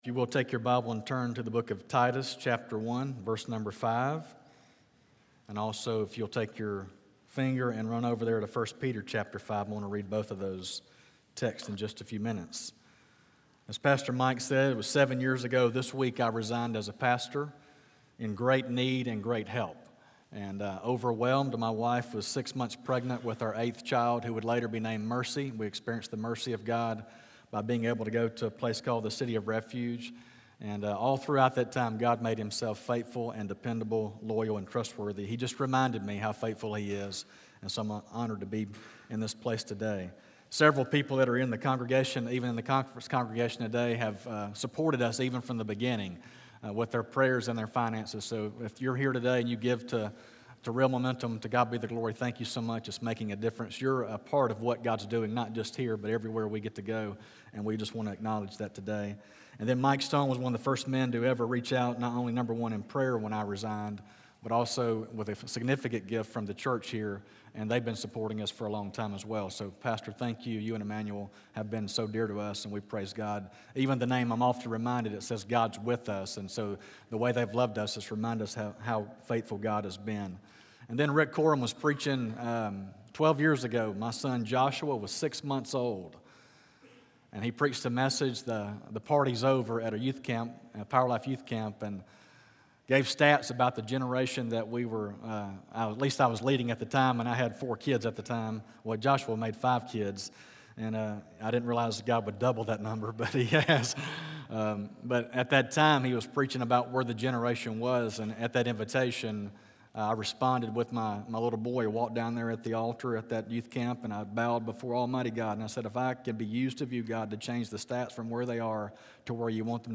Pulpit Guest Message
From the Real Momentum conference on Saturday, August 18, 2018